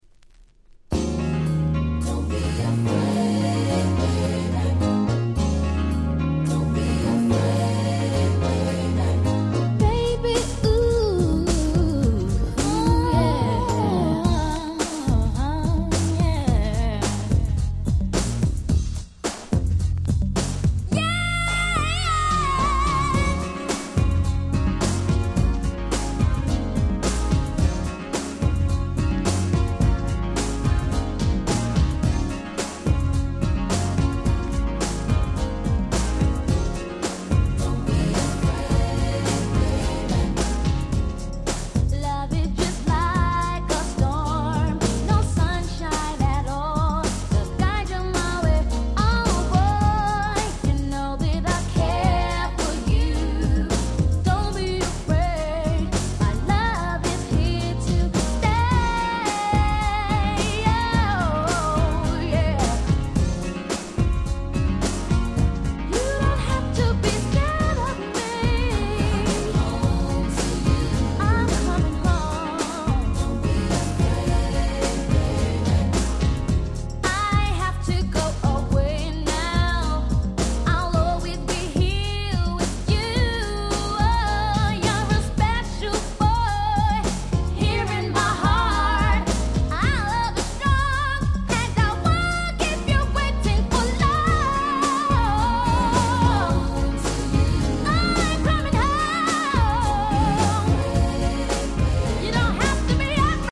【Media】Vinyl LP
【Condition】B- (薄いスリキズ多め。プチノイズ箇所あり。試聴ファイルでご確認願います。)
92' Big Hit R&B LP !!